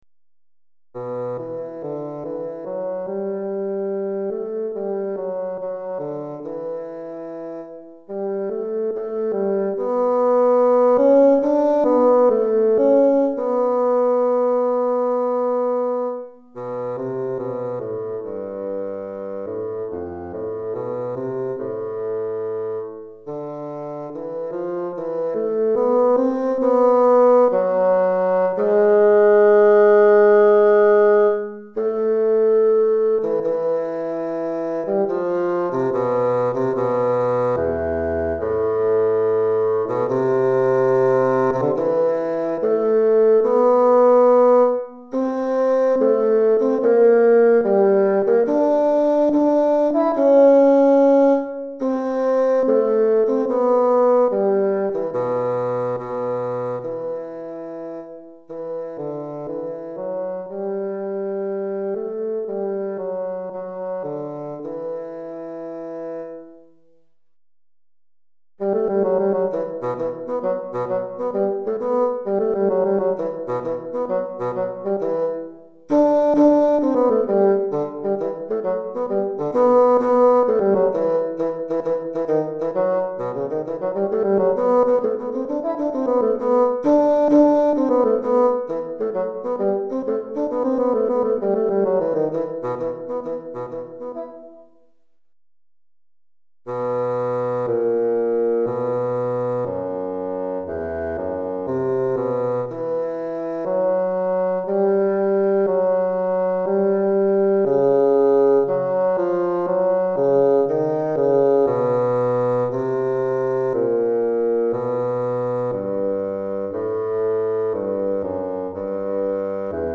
pour basson solo DEGRE CYCLE 2